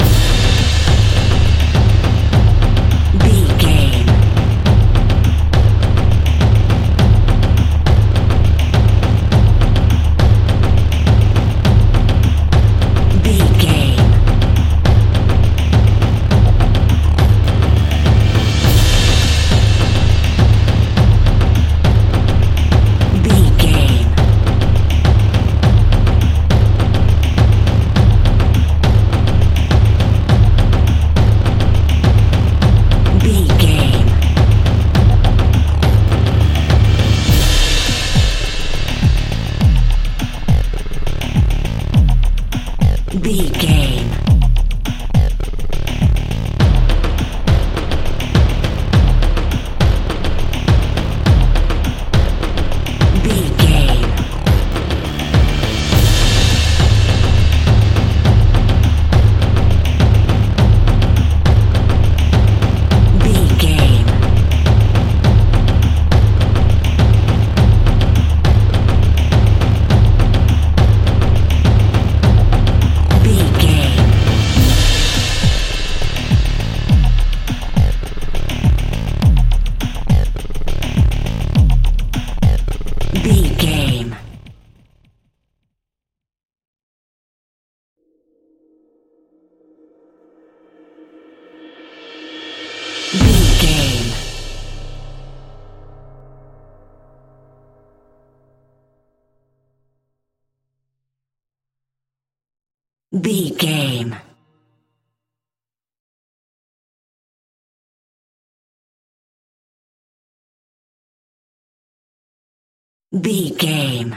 In-crescendo
Aeolian/Minor
scary
tension
ominous
dark
haunting
eerie
synthesiser
keyboards
ambience
pads
eletronic